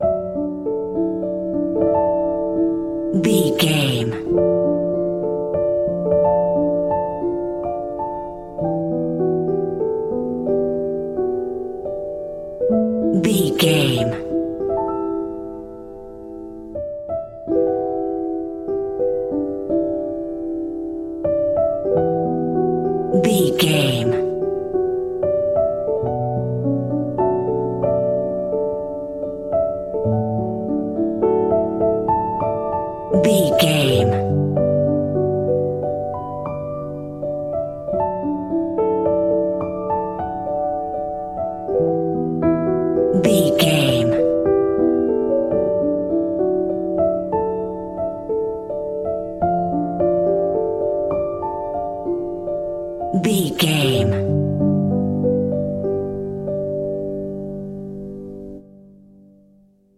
Ionian/Major
piano
contemplative
dreamy
tranquil
haunting
melancholy
ethereal